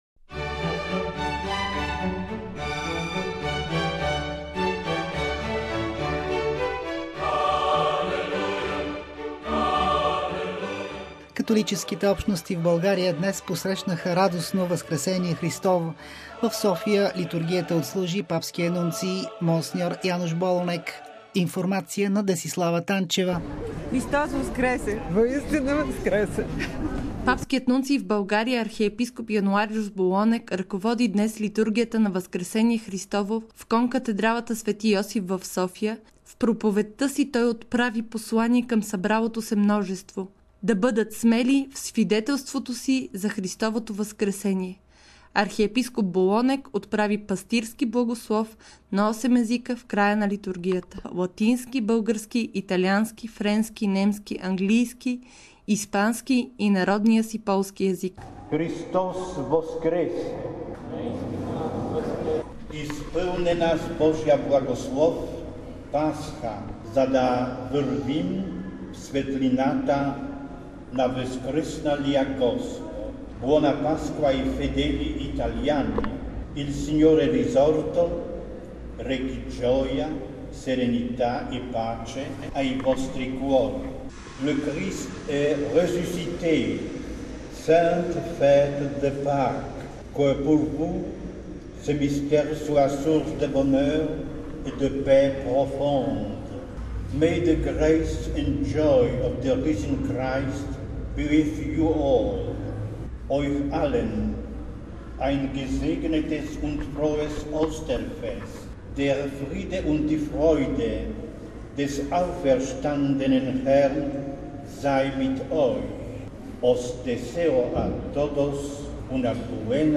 Папският нунций в България, архиепископ Януариуш Болонек ръководи днес литургията на Възкресение Христово в конкатедралата Св. Йосиф в София. В проповедта си той отправи послание към събралото се множество, да бъдат смели в свидетелството си за Христовото Възкресение."Сега и в бъдеще трябва да имаме куража на първите свидетели на Възкръсналия Господ по примера на Петър, Йоан, Павел, Магдалена и много други, които станаха вестители на Благата Вест.